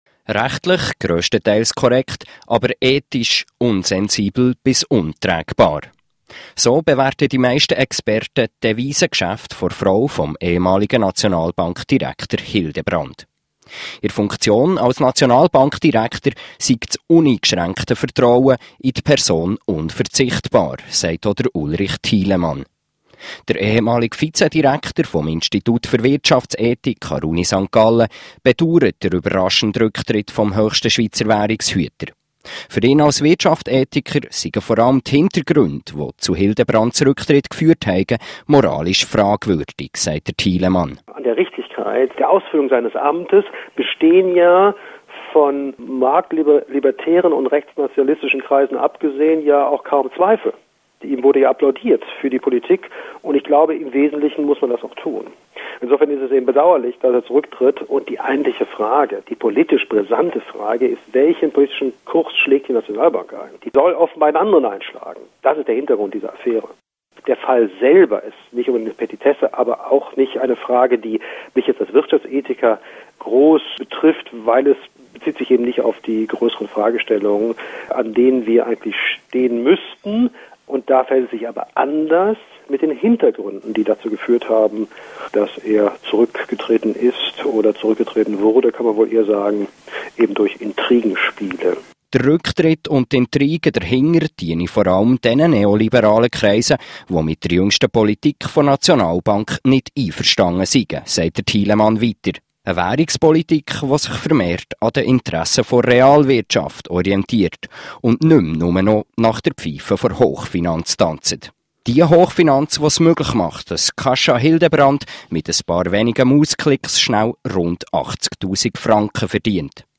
Radiointerview: Philipp Hildebrand und der Blasenkapitalismus
Vorsicht: Der Moderator spricht Schweizerdeutsch.